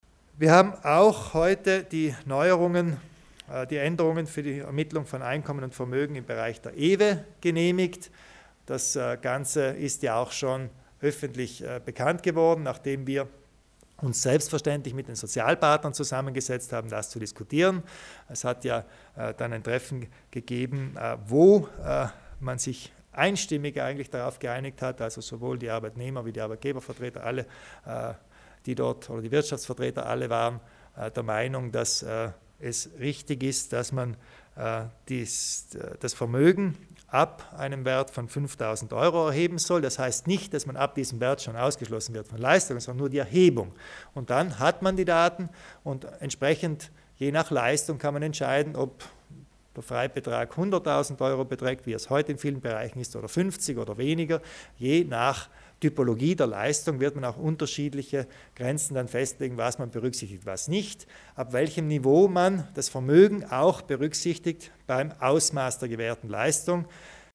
Landeshauptmann Kompatscher zu den Neuerungen in Sachen EEVE